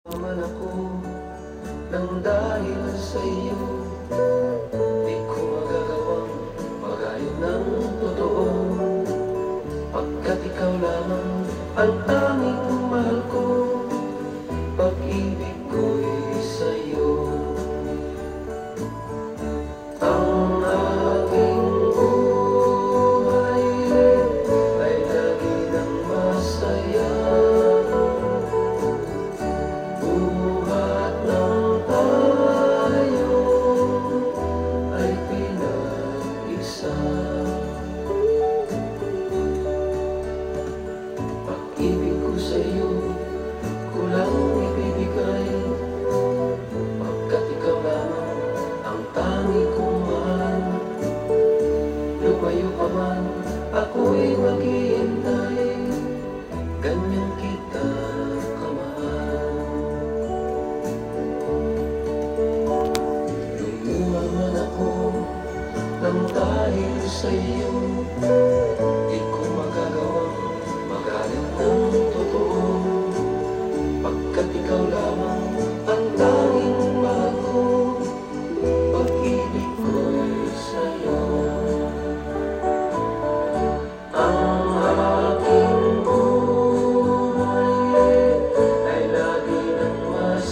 ストリングスを交えた生音主体の演奏と優しいメロディ、囁くようなテンダーなフィリピン語ボーカルが一体に。若干の歌謡曲感も◎
SOFT ROCK